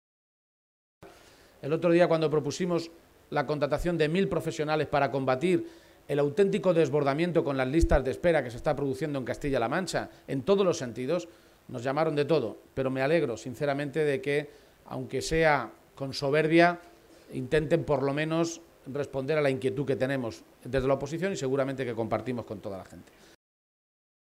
El secretario general del PSOE de Castilla-La Mancha, Emiliano García-Page, ha participado hoy en la tradicional Feria de San Isidro de Talavera de la Reina, y ha aprovechado su visita a la Ciudad de la Cerámica para compartir un encuentro con los medios de comunicación en el que ha repasado las cuestiones de actualidad regional.
Cortes de audio de la rueda de prensa